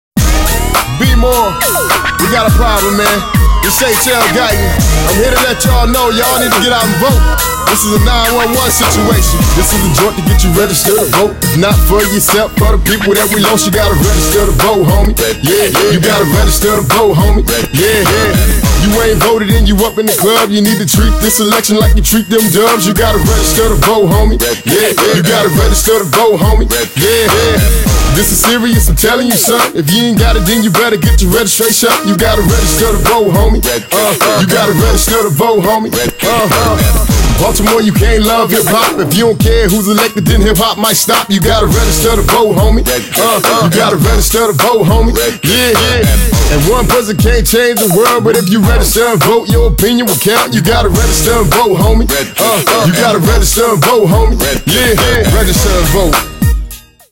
Radio advertisements